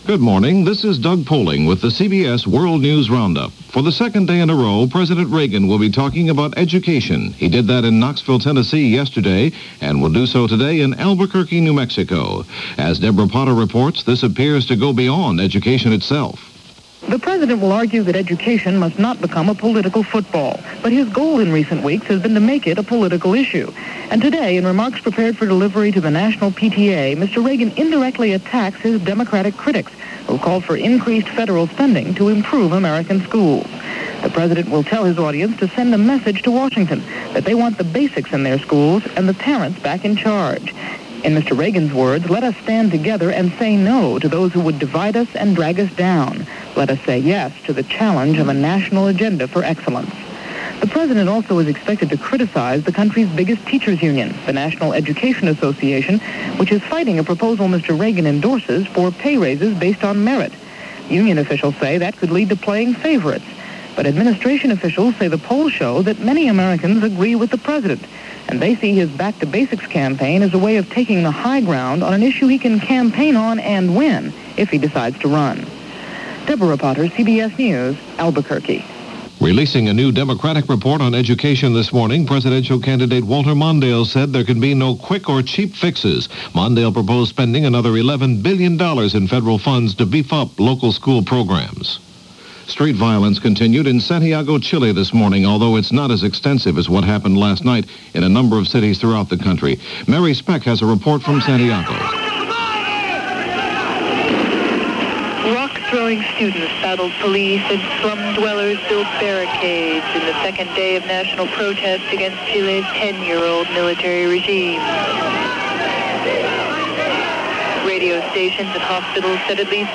June 15, 1983 - Unrest In Santiago - Reagan On Education - Charles And Diana Land In Canada - news for this day in 1983 - Past Daily.